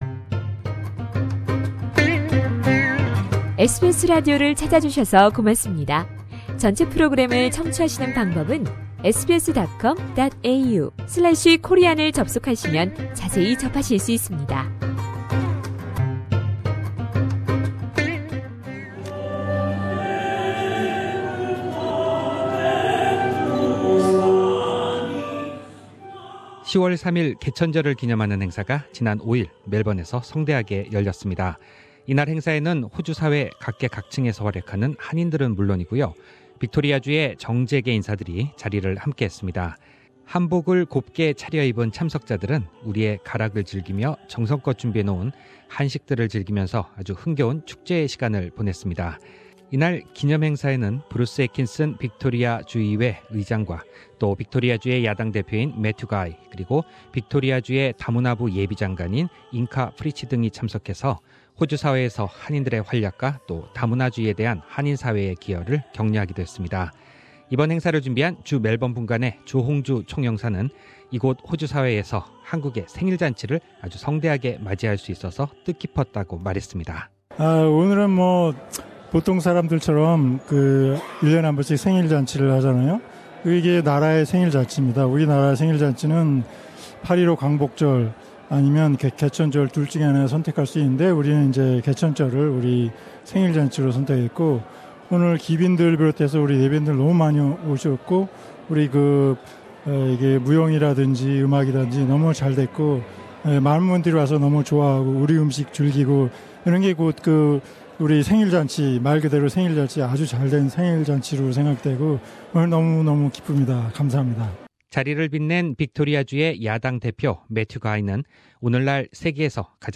The commemoration to celebrate the National foundation Day of Korea organised by consulate general of the republic of Korea in Melbourne took place.
Mr Hongju Jo, the Consulate-General of Republic of Korea in Melbourne Source: Supplied Around 260 guests came to this commemoration and Hon. Bruce Atkinson, President of Legislative Council, Hon. Matthew Guy, Leader of the Opposition and Ms Inga Peulich, Shadow Minister for Multicultural Affairs delivered congratulatory address.